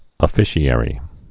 (ə-fĭshē-ĕrē)